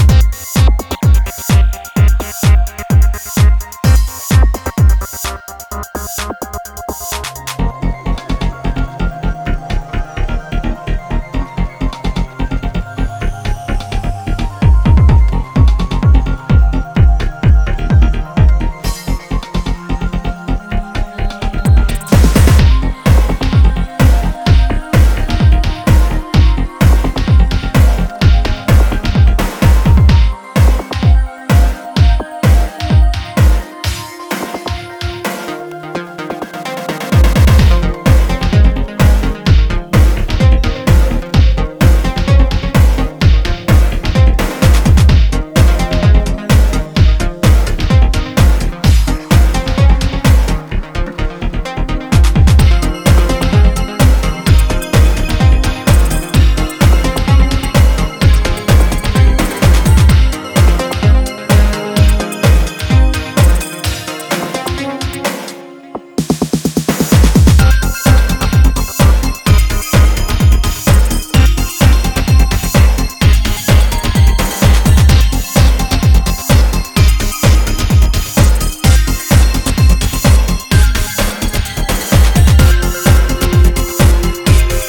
adding more punch and techno to the track and the VA.